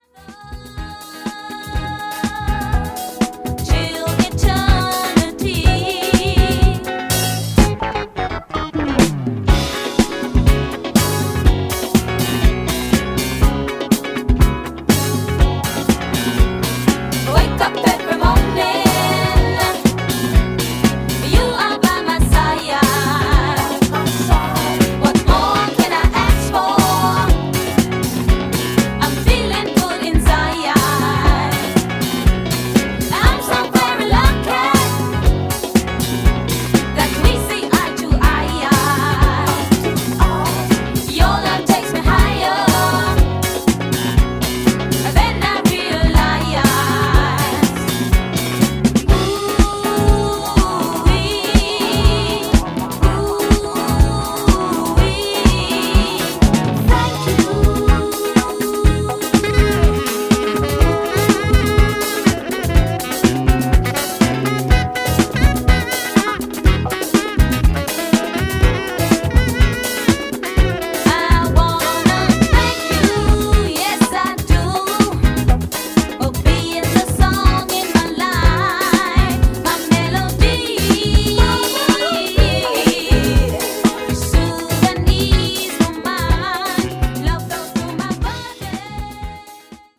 New Release Disco Classics Soul / Funk